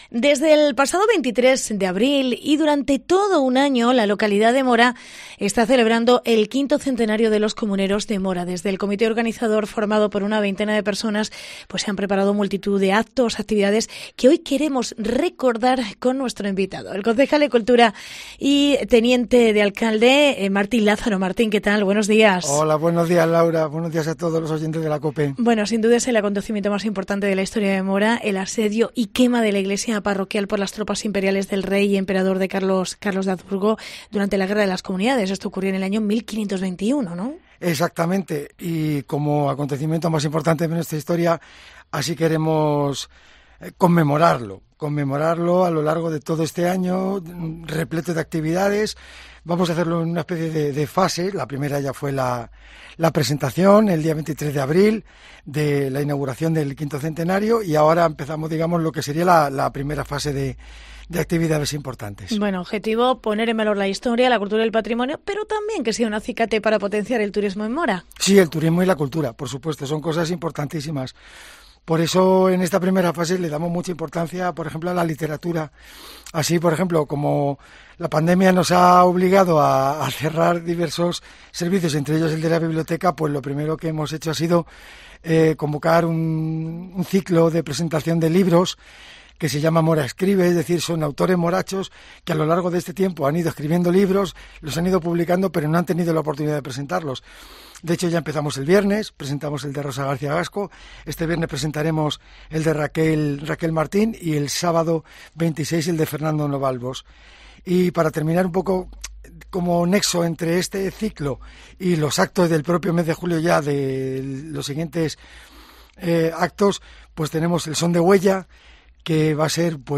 Entrevista Martín Lázaro, concejal de cultura de Mora